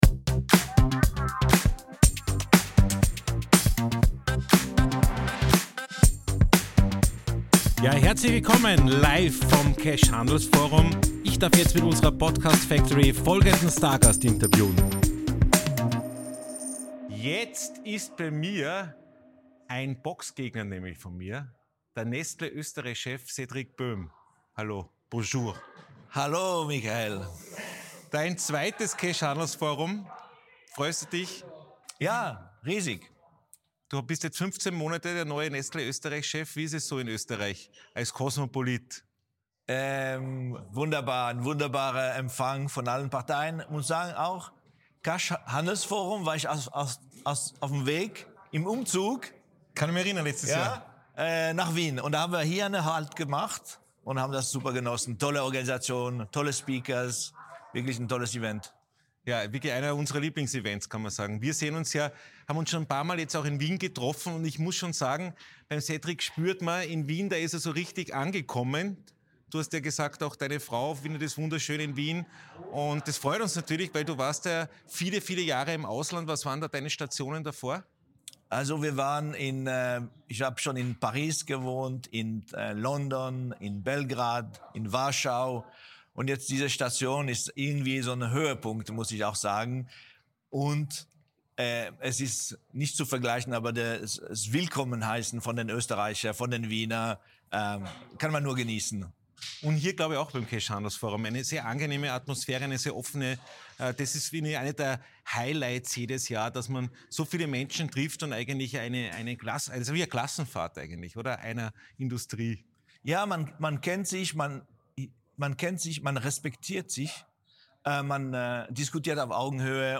Live vom CASH Handelsforum 2023 in Zusammenarbeit mit ProSiebenSat.1 PULS 4